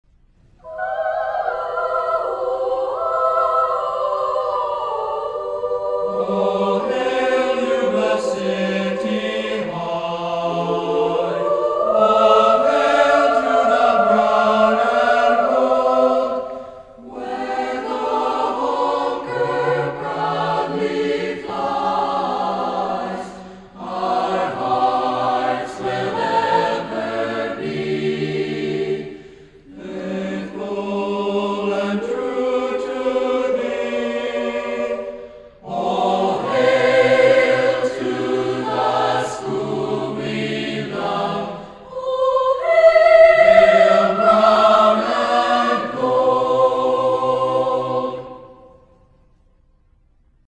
1965 Band